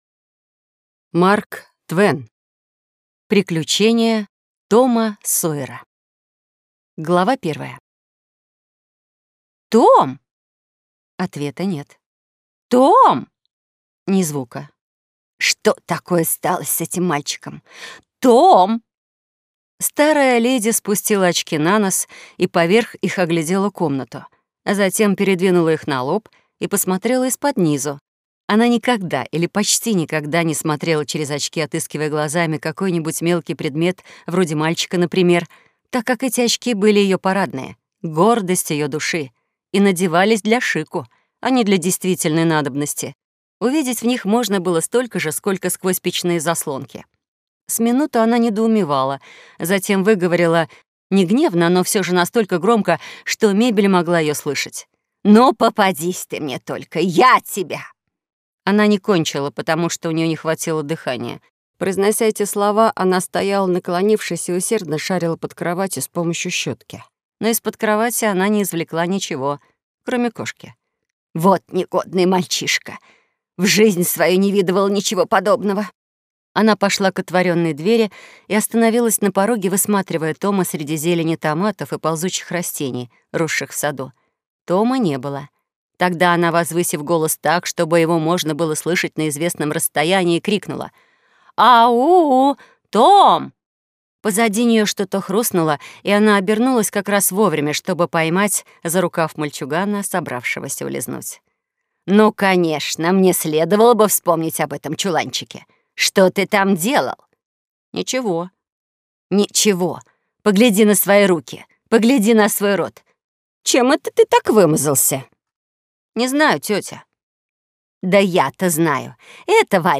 Аудиокнига Приключения Тома Сойера | Библиотека аудиокниг